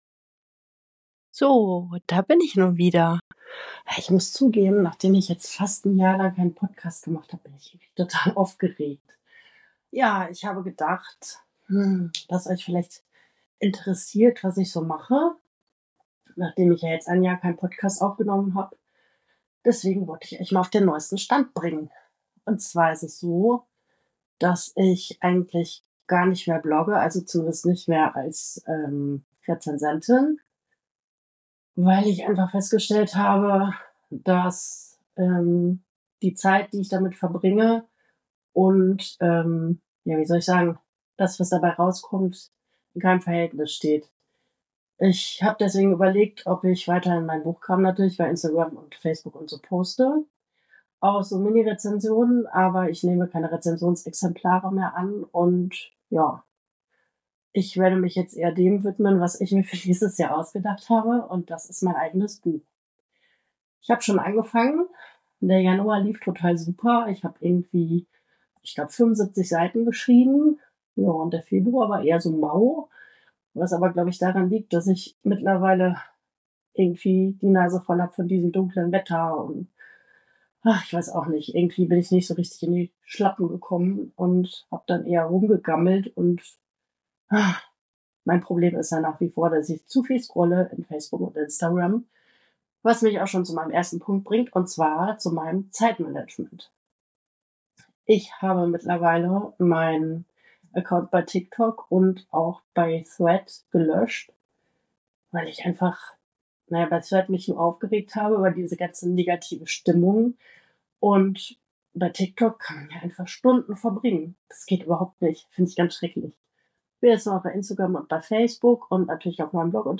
Ungeschnitten und ohne Musik.